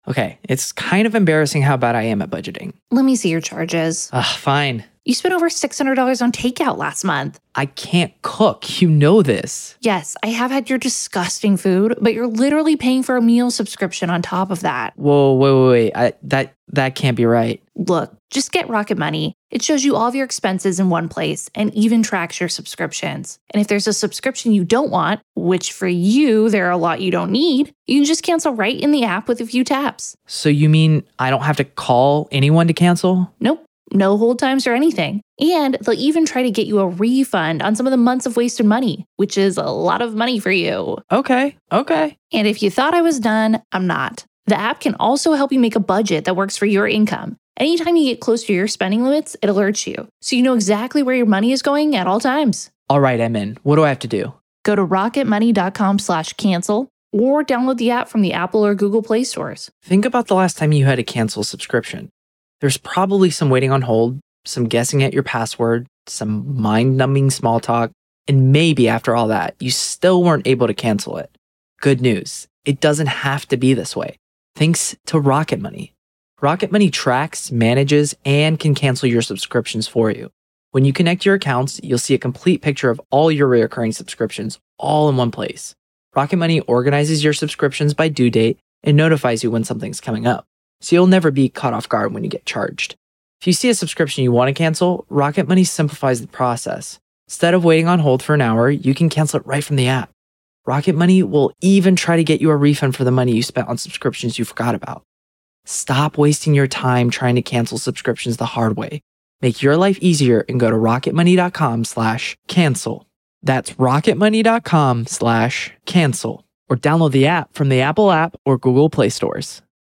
True Crime Today | Daily True Crime News & Interviews / Inside the Mind of Sean “Diddy” Combs: Power, Control & the Psychology of Alleged Abuse | 2025 Year in Review